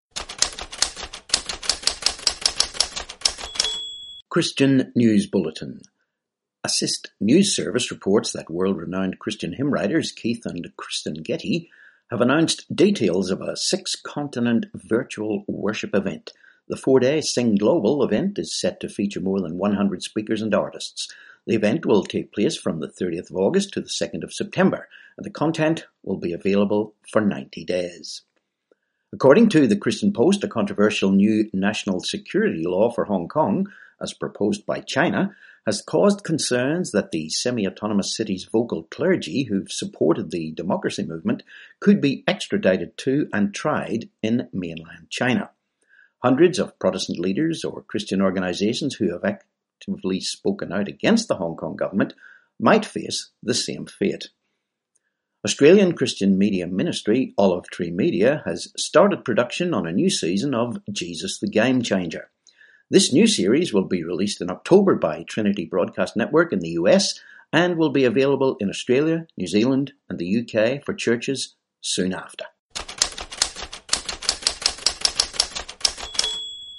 5Jul20 Christian News Bulletin